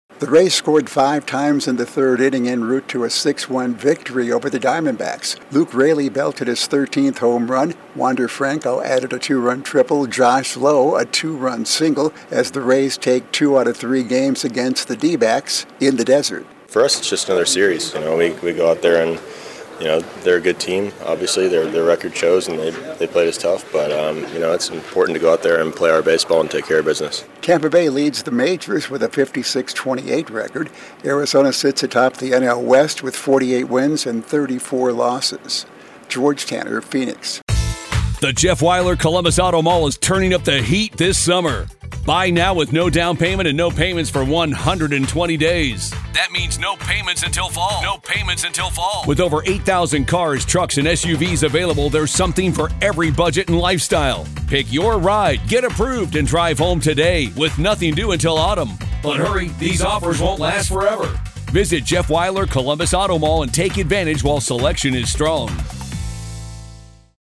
The Rays take control early in a win over the Diamondbacks. Correspondent